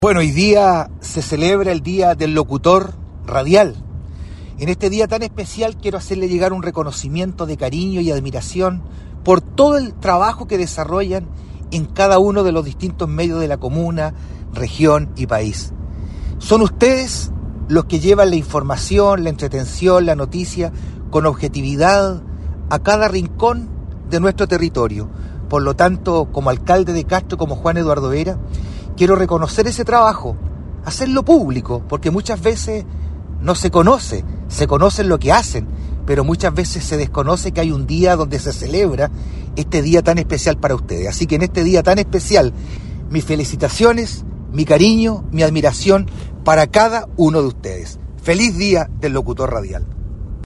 Alcalde Vera reconoció públicamente el tremendo trabajo de los locutores radiales
ALCALDE-VERA-DIA-DEL-LOCUTOR-RADIAL.mp3